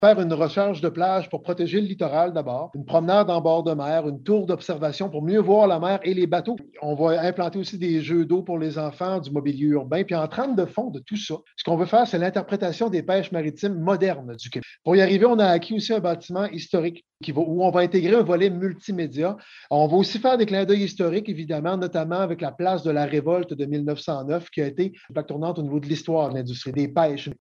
Le maire de Gaspé, Daniel Côté,  parle d’une première phase qui va comporter un aspect historique avec un écomusée sur les pêches et un réaménagement de tout le secteur. :